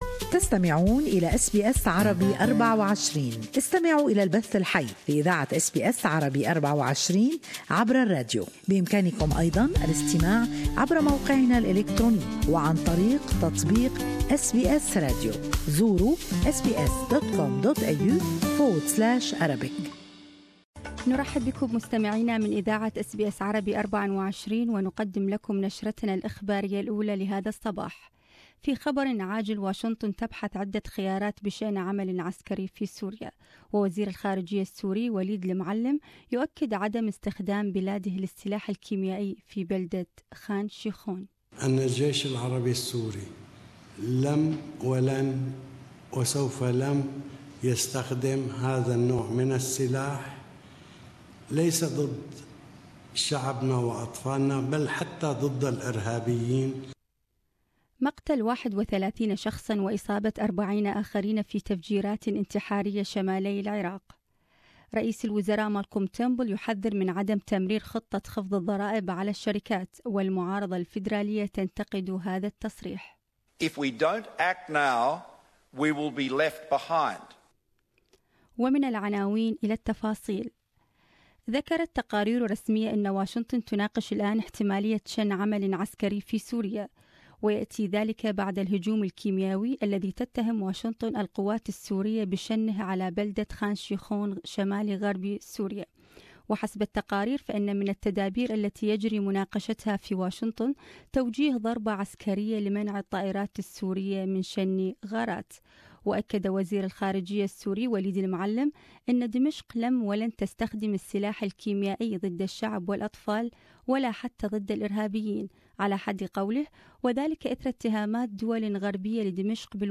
Latest Australian and World news in SBS Arabic 24 morning news bulletin.